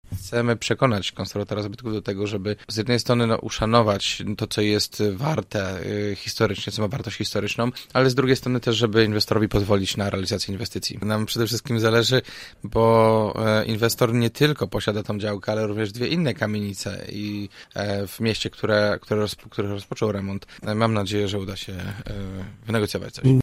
W innym przypadku ruiny przy walczaka będą straszyć przez kolejnych kilkadziesiąt lat- mówił dziś w naszym studiu Jacek Wójcicki.